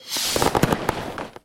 fireworks.mp3